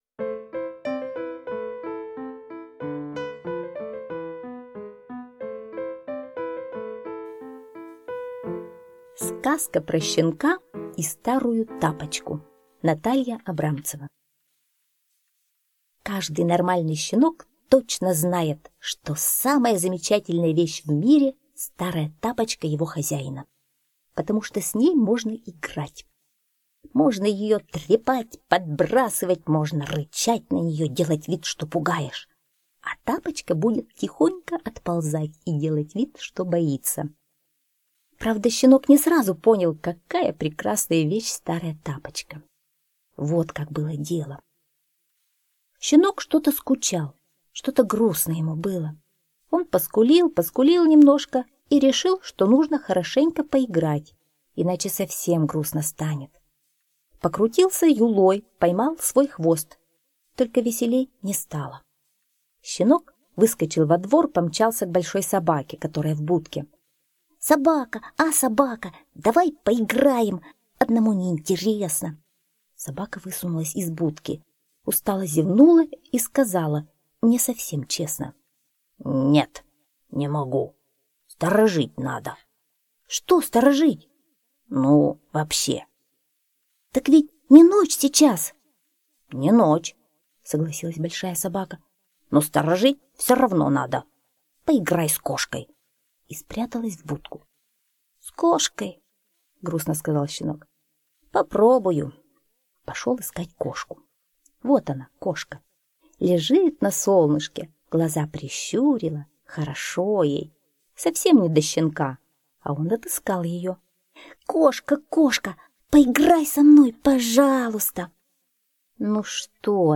Сказка про щенка и старую тапочку (аудиоверсия)
Аудиокнига в разделах